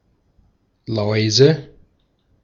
Ääntäminen
Ääntäminen Tuntematon aksentti: IPA: /ˈlɔɪ̯zə/ Haettu sana löytyi näillä lähdekielillä: saksa Käännöksiä ei löytynyt valitulle kohdekielelle. Läuse on sanan Laus monikko.